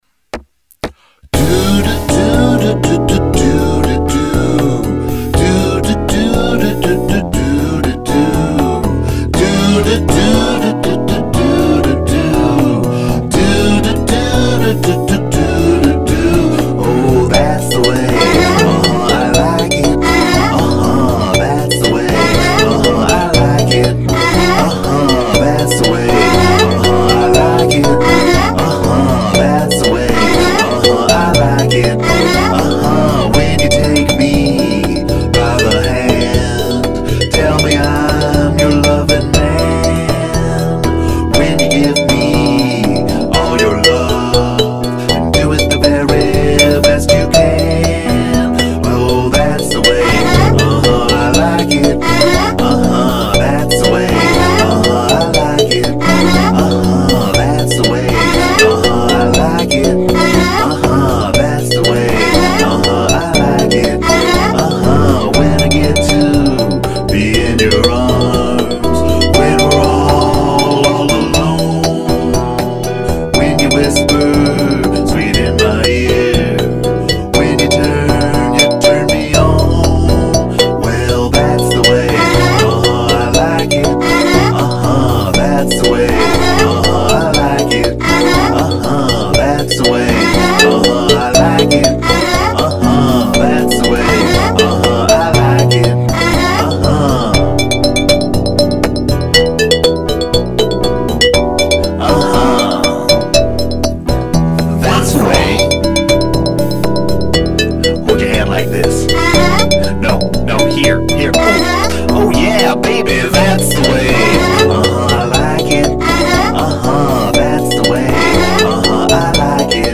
Disco!
• My daughter's instruments - Marimba
• Samples used - None